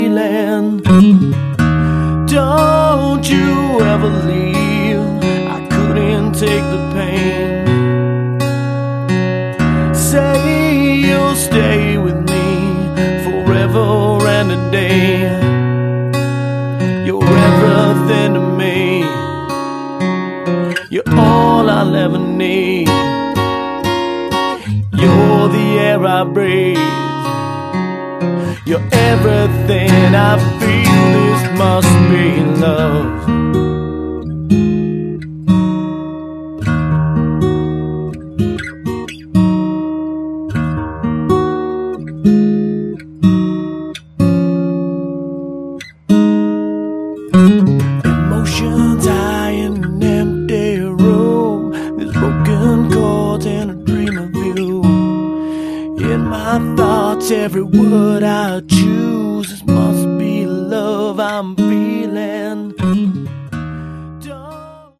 Category: AOR
vocals
guitar
drums
bass